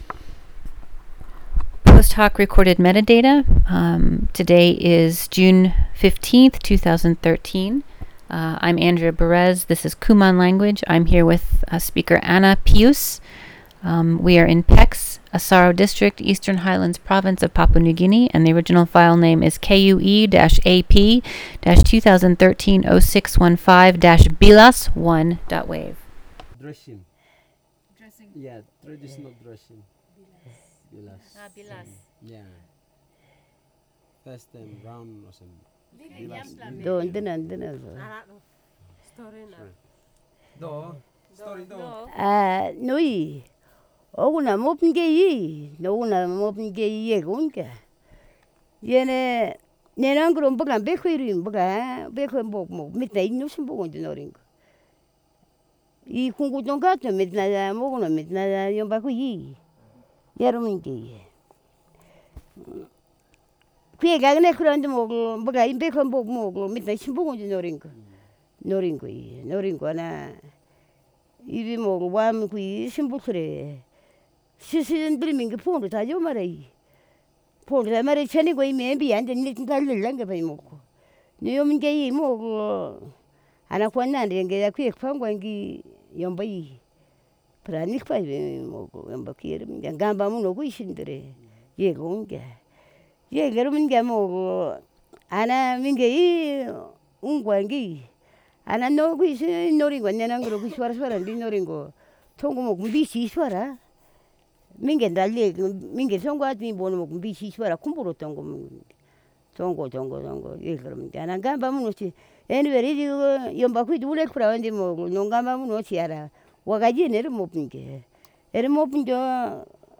digital wav file recorded at 44.1 kHz/16 bit on Zoom H4n solid state recorder with Countryman e6 headset microphone
Pex Village, Asaro District, Eastern Highlands Province, Papua New Guinea